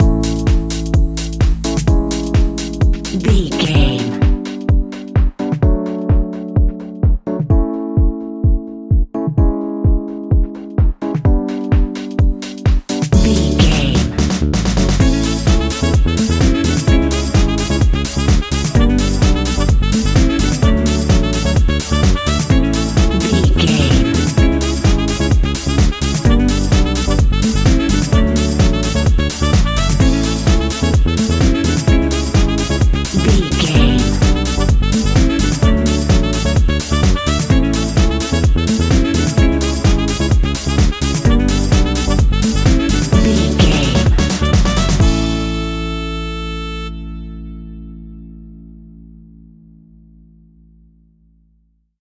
Ionian/Major
SEAMLESS LOOPING?
groovy
uplifting
bouncy
cheerful/happy
electric guitar
horns
drums
bass guitar
saxophone
nu disco
upbeat
clavinet
fender rhodes
synth bass